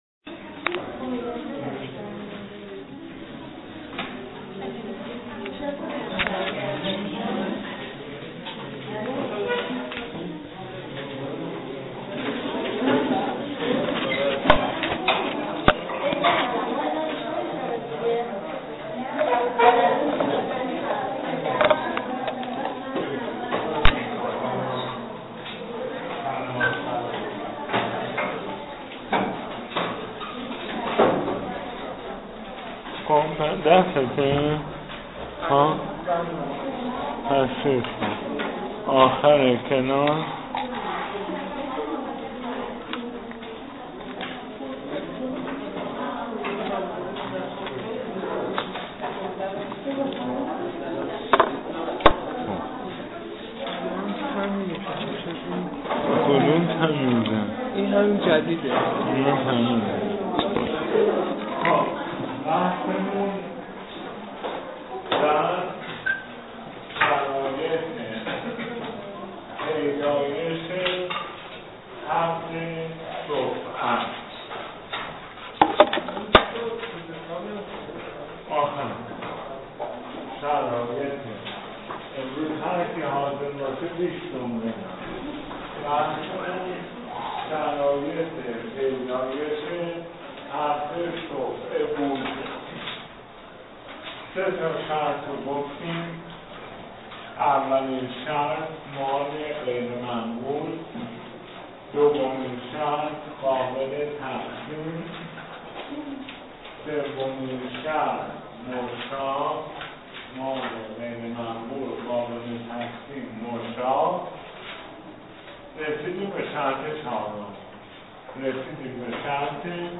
تدریس در دانشگاه